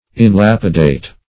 Search Result for " inlapidate" : The Collaborative International Dictionary of English v.0.48: Inlapidate \In*lap"i*date\, v. t. [Pref. in- in + L. lapis, lapidis, stone.]